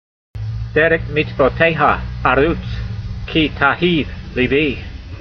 v32_voice.mp3